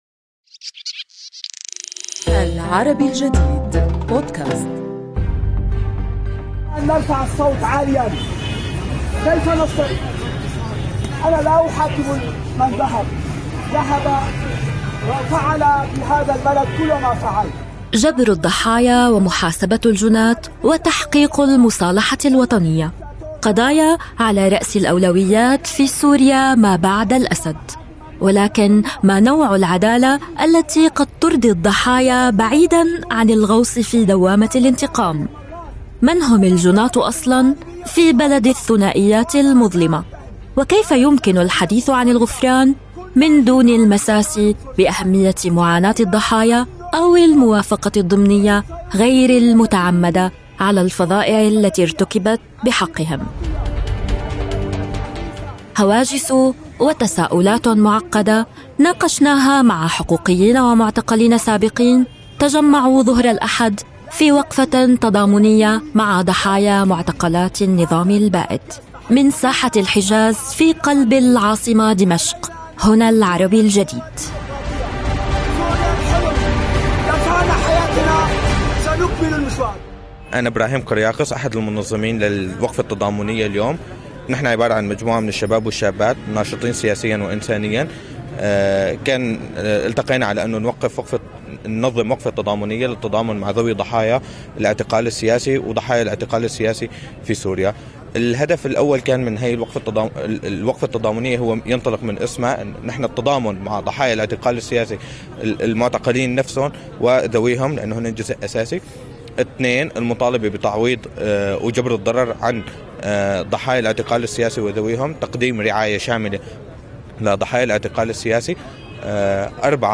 هواجس وتساؤلات معقدة ناقشناها مع حقوقيين ومعتقلين سابقين تجمعوا، ظهر الأحد، في وقفة تضامنية مع ضحايا معتقلات النظام البائد. من ساحة الحجاز في قلب العاصمة دمشق، هنا "العربي الجديد".